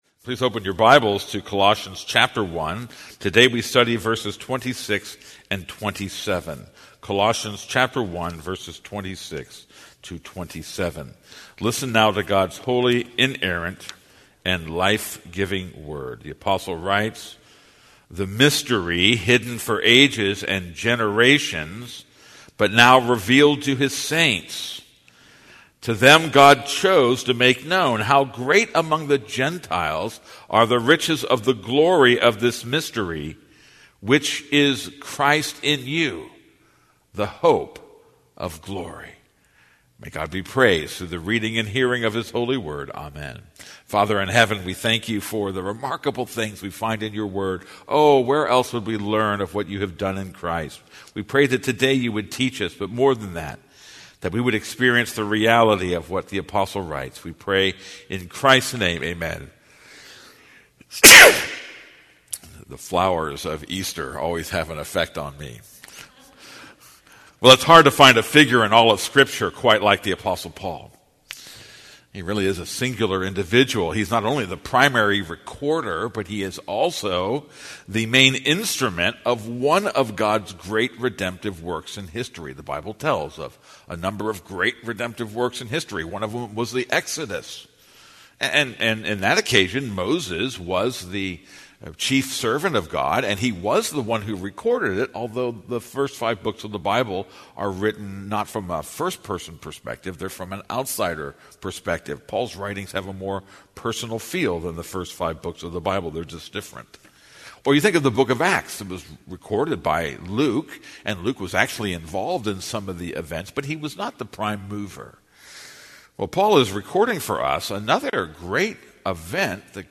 This is a sermon on Colossians 1:26-27.